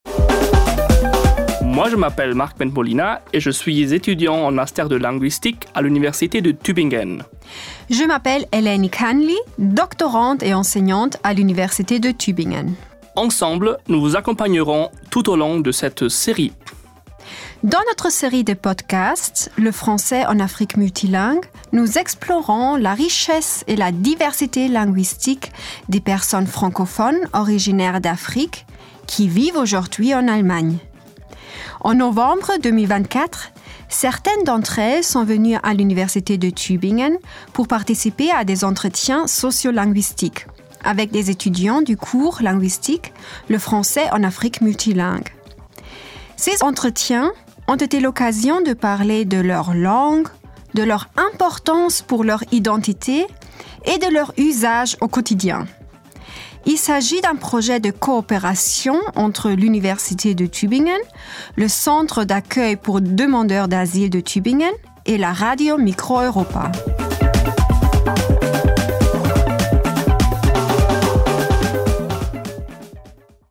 Einige von ihnen kamen im November 2024 an die Universität Tübingen, um an soziolinguistischen Interviews mit Studierenden des Linguistikkurses „Französisch im mehrsprachigen Afrika“ teilzunehmen. Diese Interviews waren eine Gelegenheit, über ihre Sprachen, ihre Bedeutung für ihre Identität und ihren täglichen Gebrauch zu sprechen.